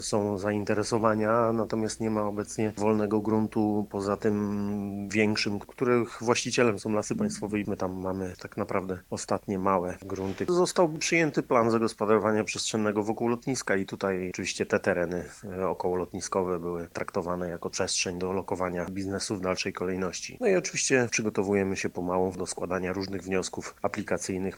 W działającym pod miastem parku przemysłowym, jak przyznaje wiceburmistrz Piotr Wolny, nie zostało wiele miejsca na nowe zakłady, a gmina chce stworzyć nową strefę dla nich.